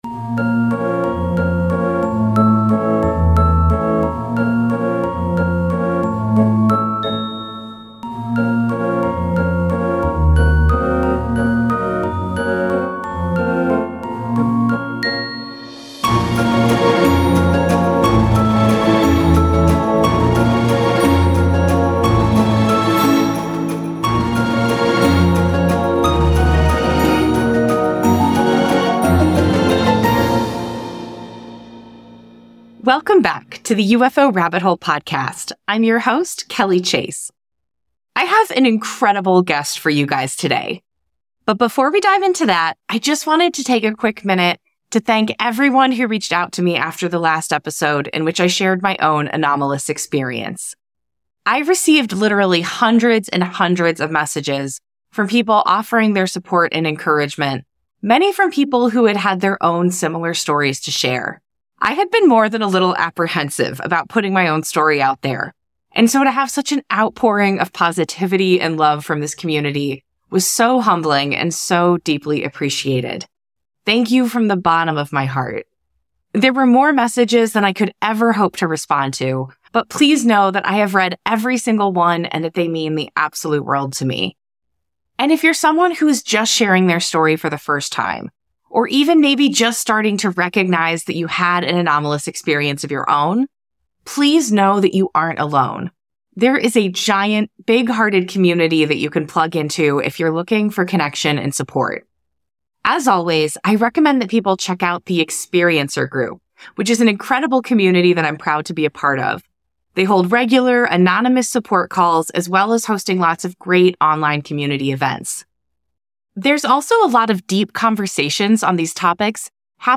In today’s episode, I had the absolute honor of speaking with one of my personal intellectual heroes, Bernardo Kastrup.